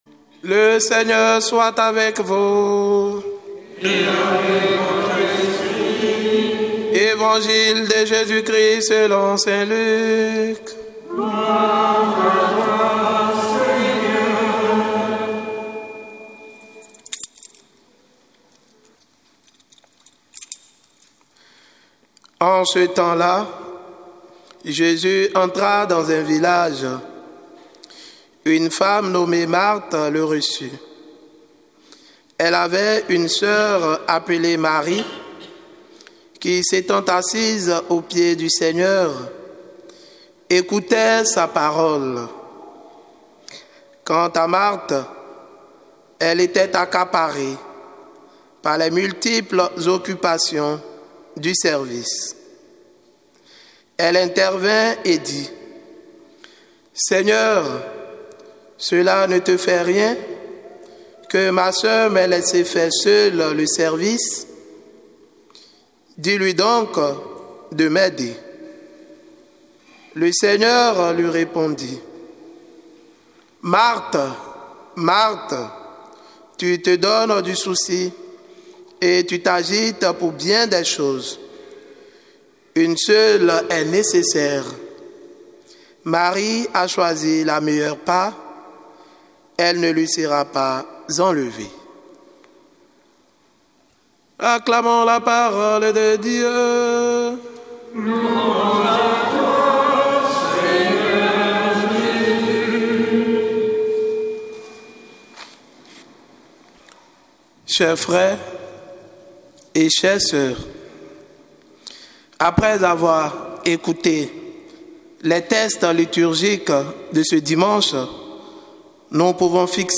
Évangile de Jésus Christ selon saint Luc avec l'homélie